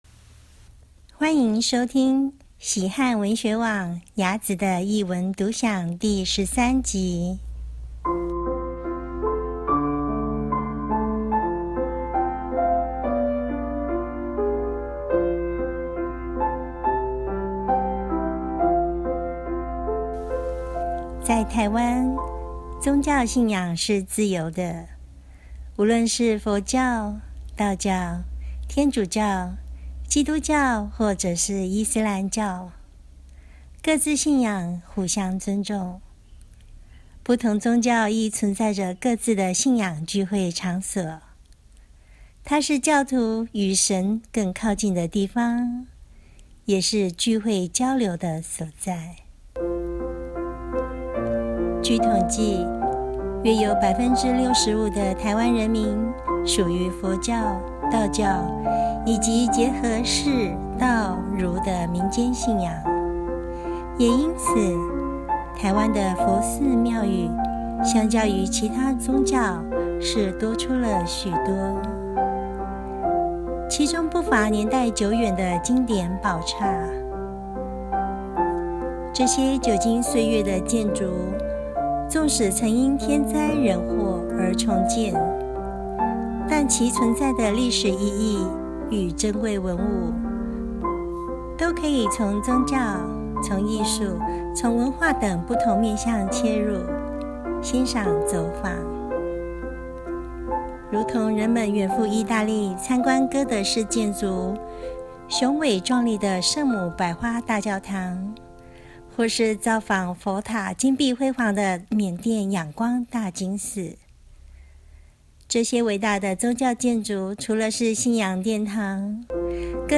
音樂演奏